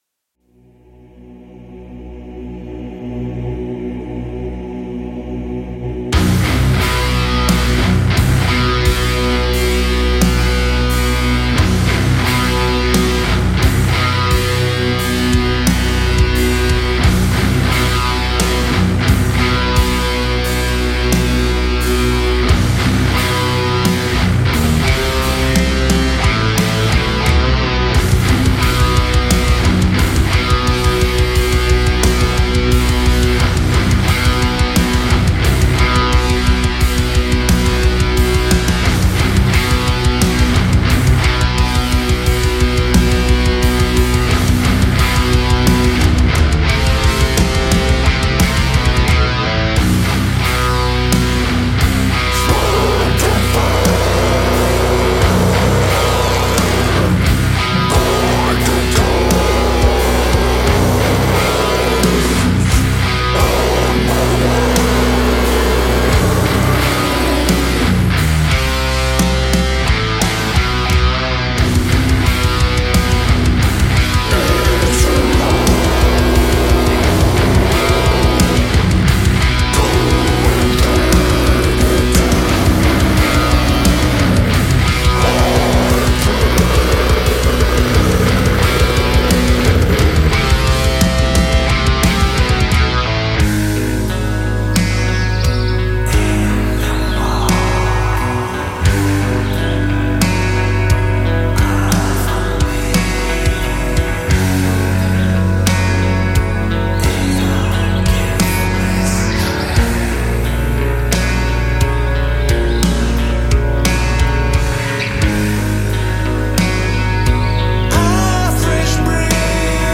Brutal Belarusian Death Metal Unleashed